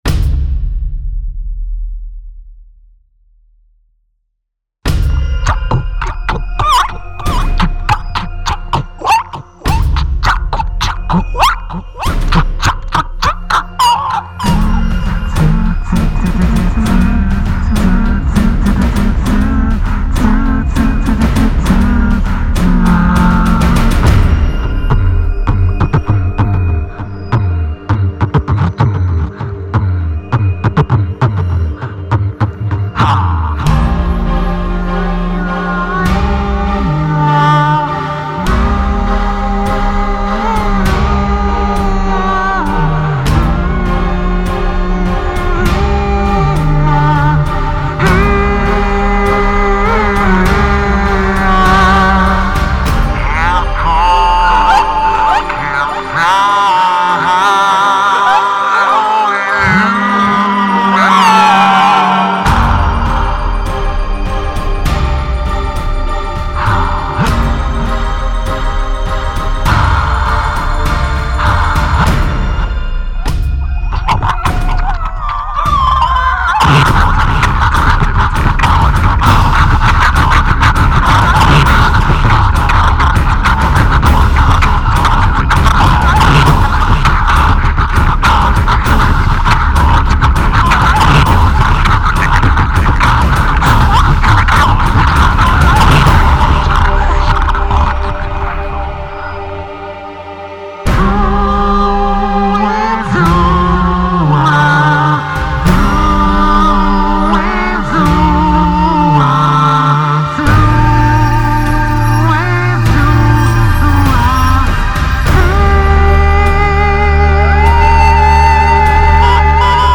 much more minimalistic and experimental